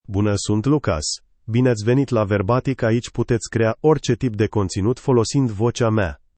Lucas — Male Romanian (Romania) AI Voice | TTS, Voice Cloning & Video | Verbatik AI
LucasMale Romanian AI voice
Voice sample
Listen to Lucas's male Romanian voice.
Male
Lucas delivers clear pronunciation with authentic Romania Romanian intonation, making your content sound professionally produced.